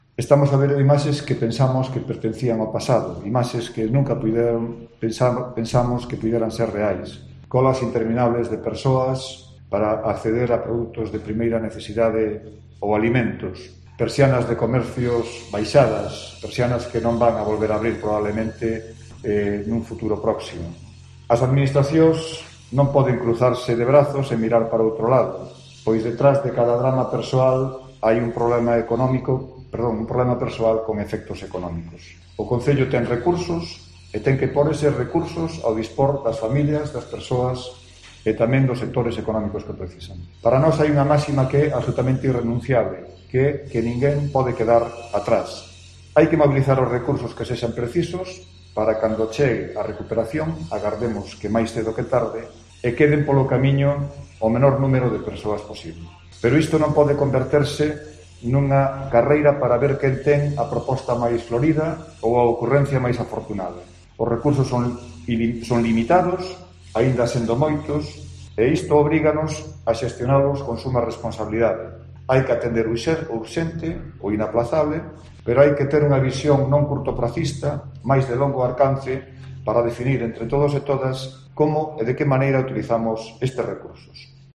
Declaraciones de Luis Seara sobre la propuesta que van a defender en el Pleno del día 4 de diciembre